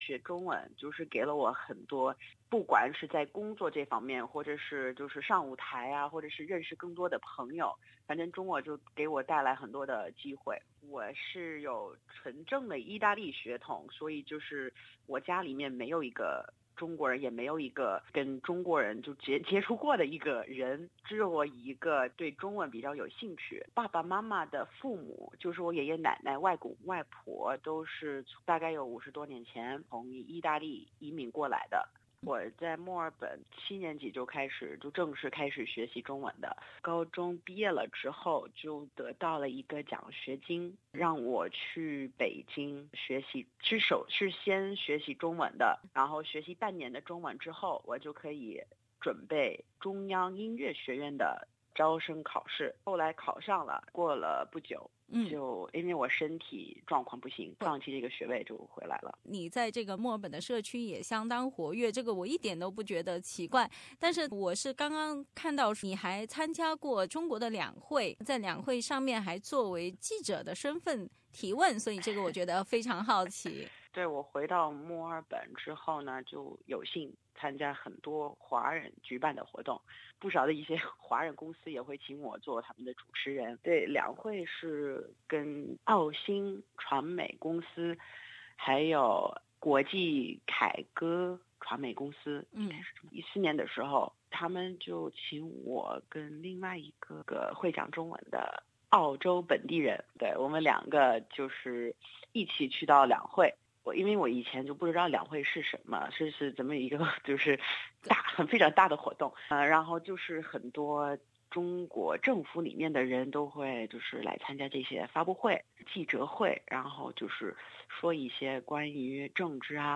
作为一个普通话流利的意大利墨尔本人